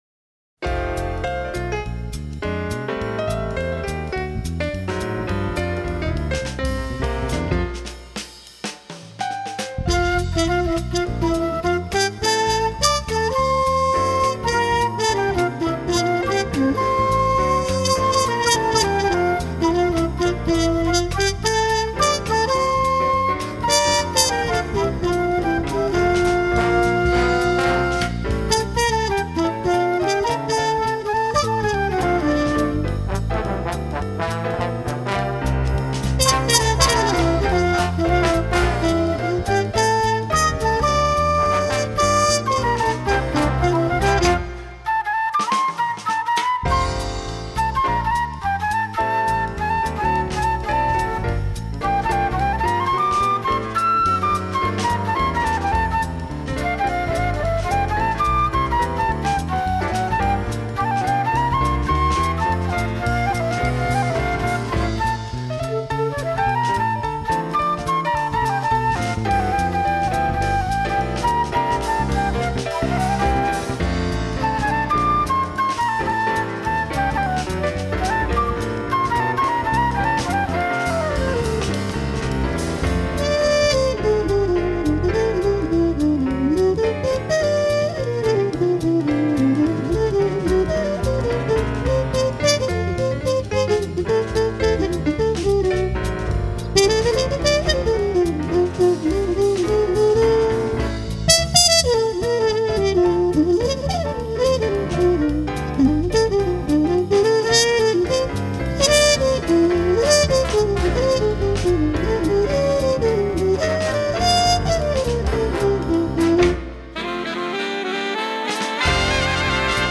MUSIC FOR BIG BAND
Category: Big Band